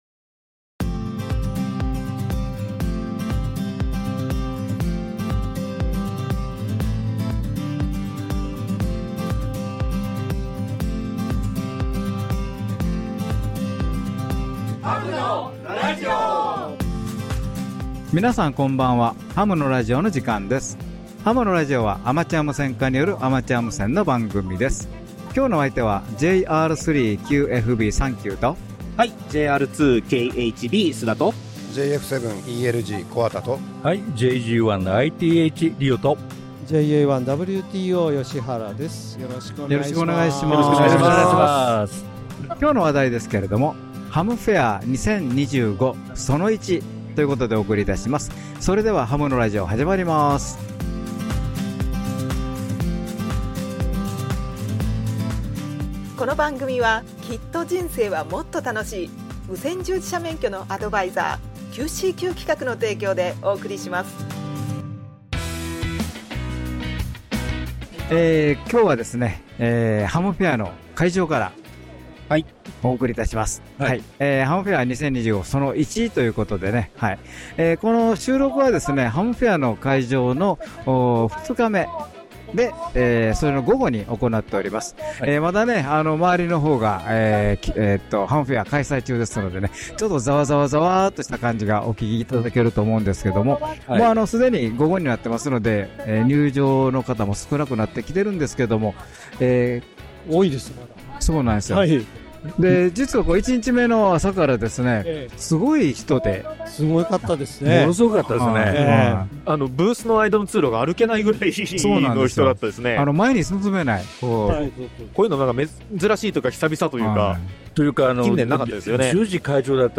今回の特集は「ハムフェア2025 その1」です。 ハムフェアに出展されていたメーカーさん、クラブの皆さんに 会場でインタビューさせていただきました。